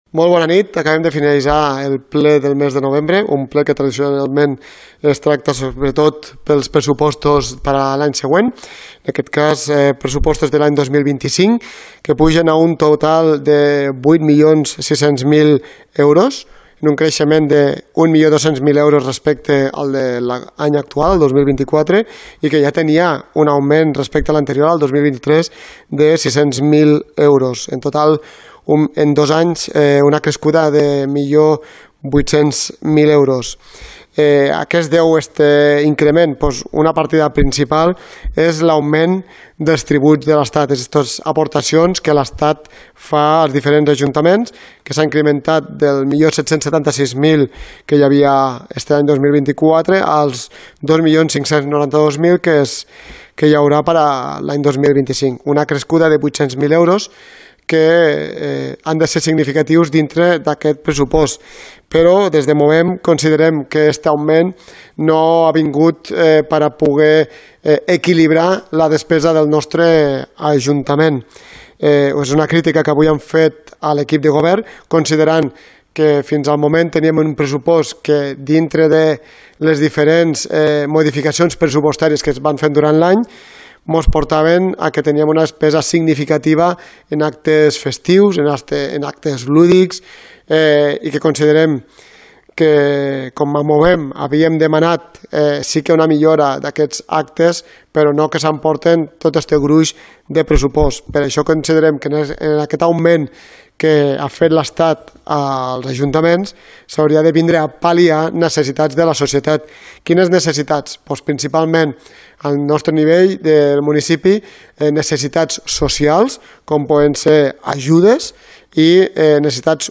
Ple Ordinari de Roquetes – Novembre 2024 – Declaracions – Movem Roquetes – David Poy | Antena Caro - Roquetes comunicació